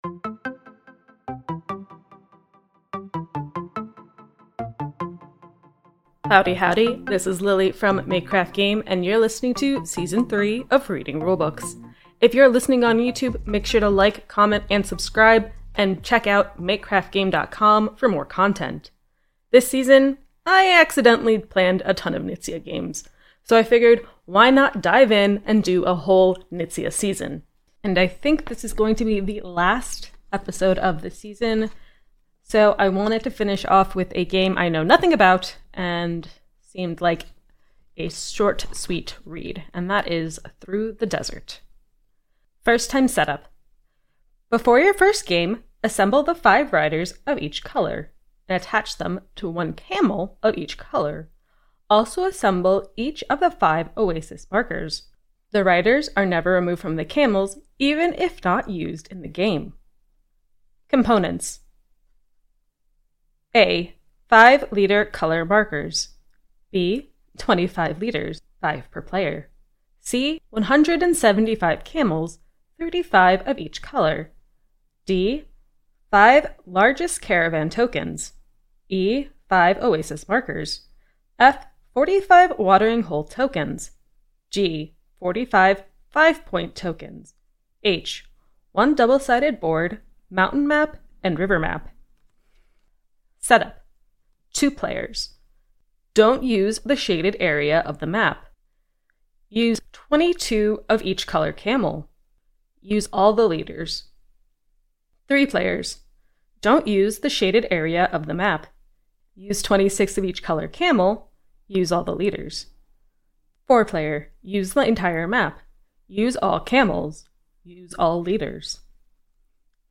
Reading Rulebooks is a podcast dedicated to reading through rulebooks and teaching board games! In this podcast, I directly read the rulebook as well as discuss the game and try to clear up potential areas of confusion.